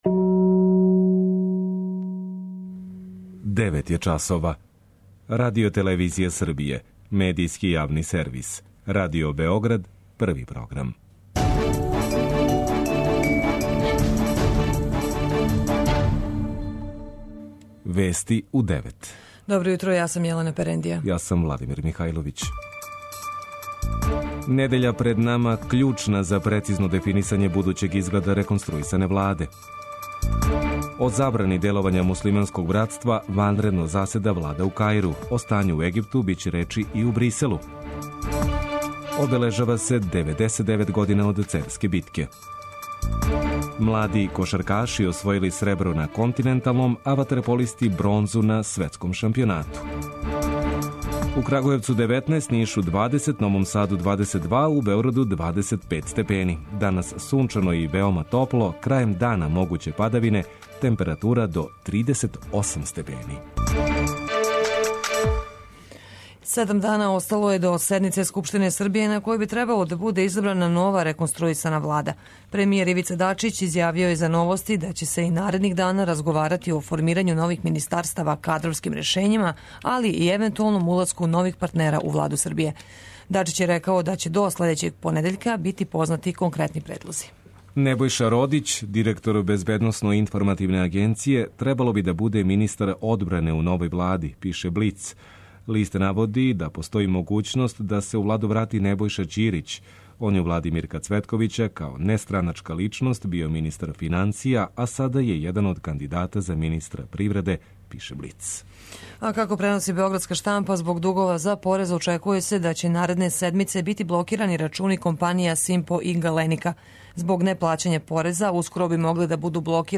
преузми : 10.35 MB Вести у 9 Autor: разни аутори Преглед најважнијиx информација из земље из света.